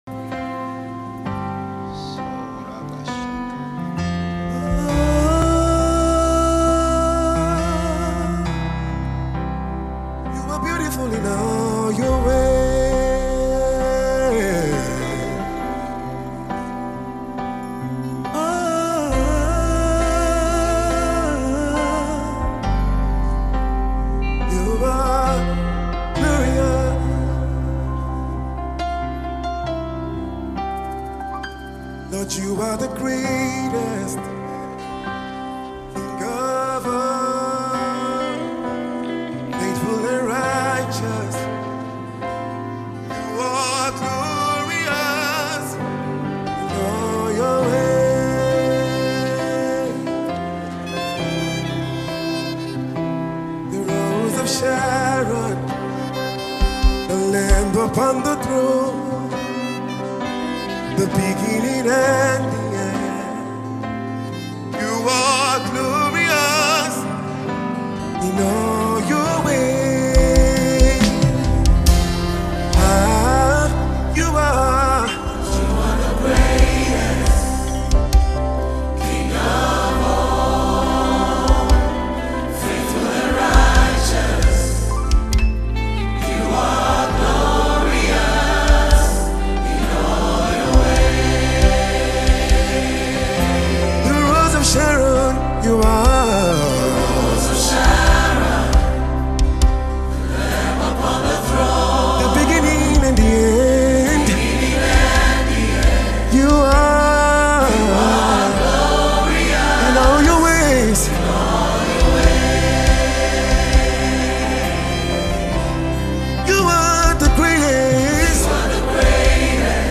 February 11, 2025 Publisher 01 Gospel 0